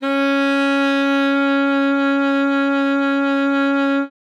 42c-sax05-c#4.wav